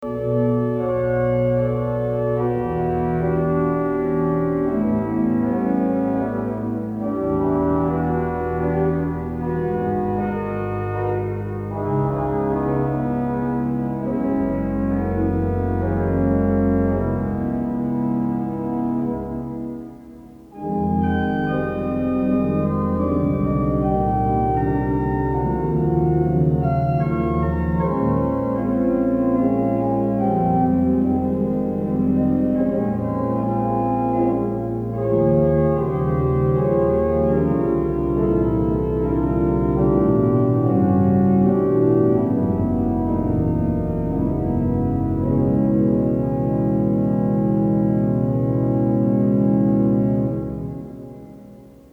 My 'Perfect Gardens' for organ won first prize in the Garden of England Awards.